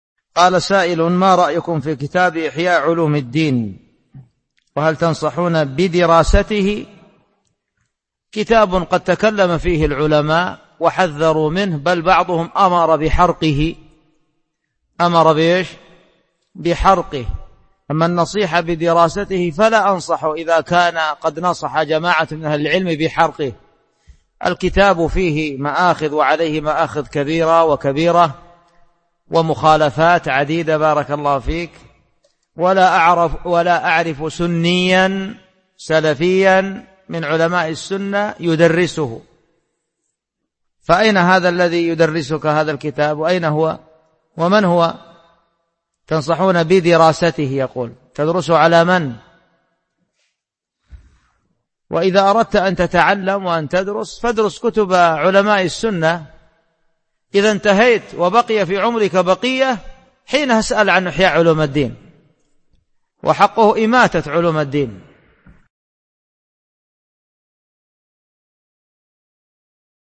ما رأيكم في كتاب إحياء علوم الدين للغزالي؟ Album: موقع النهج الواضح Length: 1:10 minutes (352.26 KB) Format: MP3 Mono 22kHz 32Kbps (VBR)